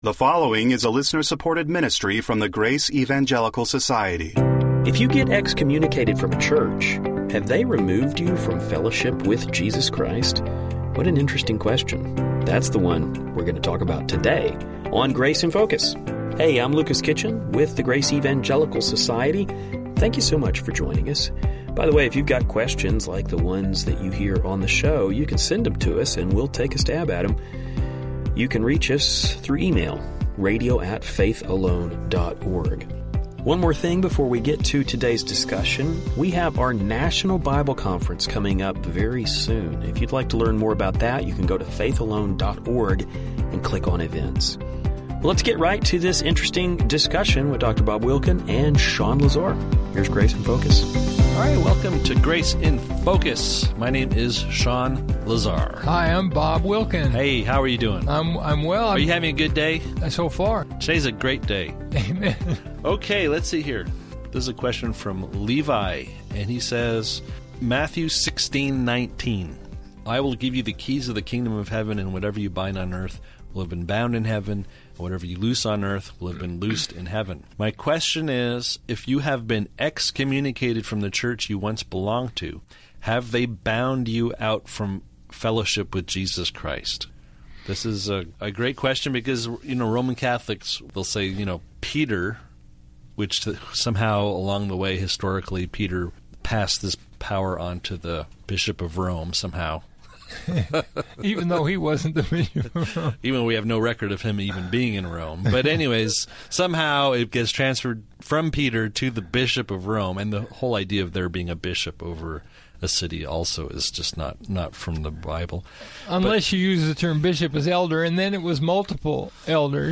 currently taking questions from our listeners